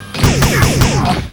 accretia_guardtower_attack.wav